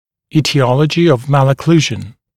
[ˌiːtɪ’ɔləʤɪ əv ˌmælə’kluːʒn] [ˌи:ти’олэджи ов ˌмэлэ’клу:жн] этиология аномалии прикуса (US etiology of malocclusion)